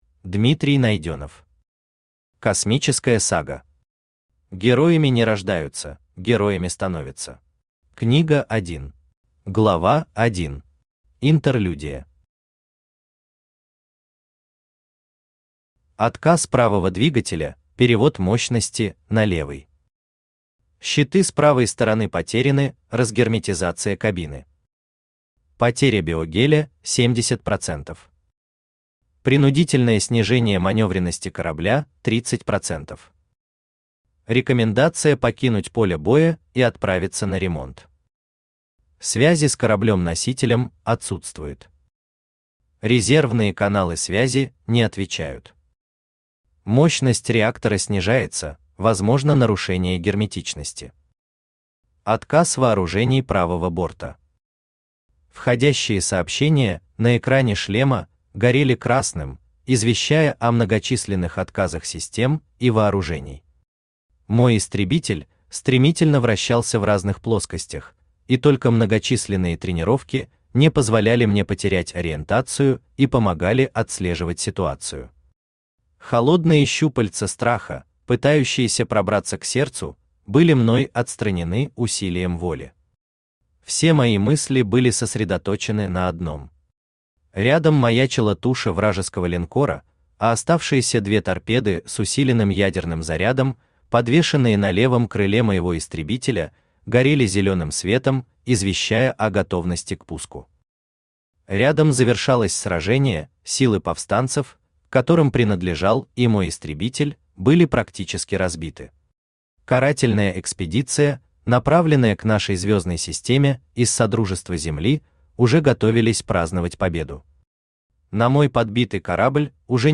Аудиокнига Космическая сага. Героями не рождаются, Героями становятся. Книга 1 | Библиотека аудиокниг
Книга 1 Автор Дмитрий Александрович Найденов Читает аудиокнигу Авточтец ЛитРес.